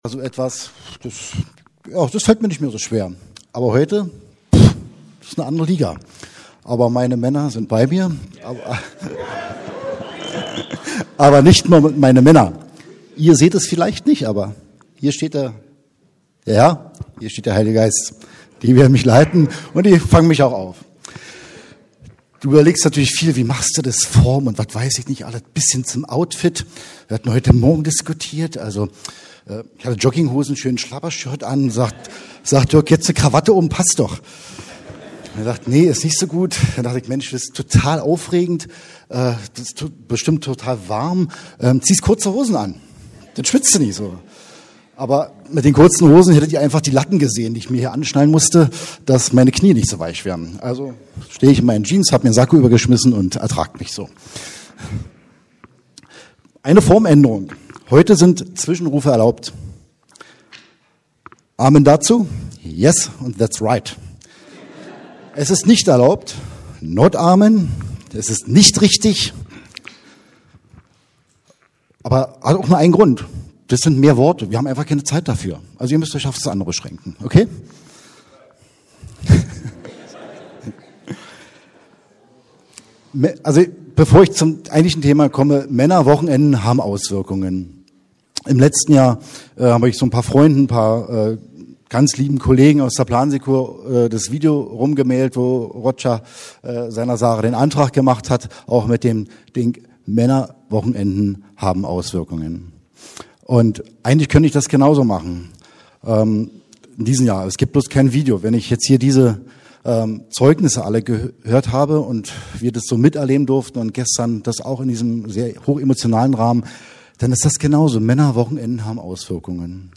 Versöhnung unter den Generationen ~ Predigten der LUKAS GEMEINDE Podcast